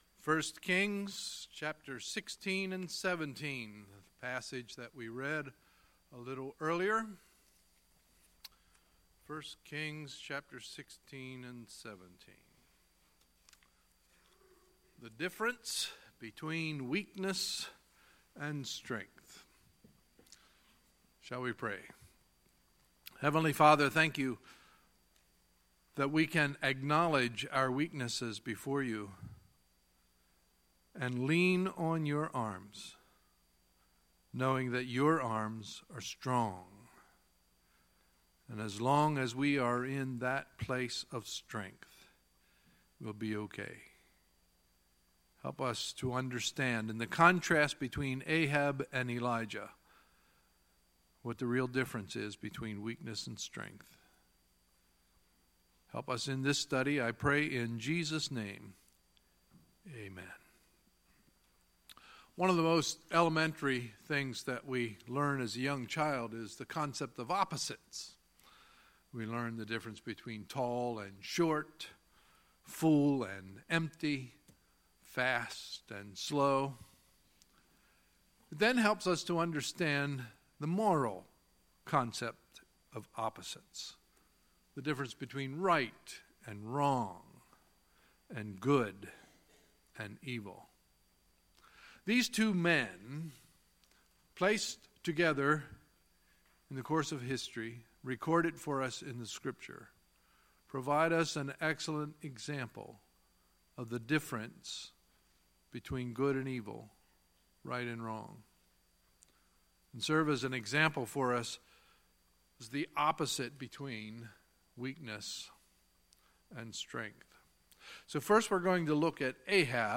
Sunday, October 29, 2017 – Sunday Morning Service